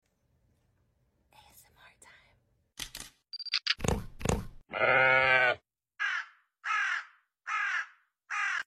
Subscribe for satisfying crunchy bone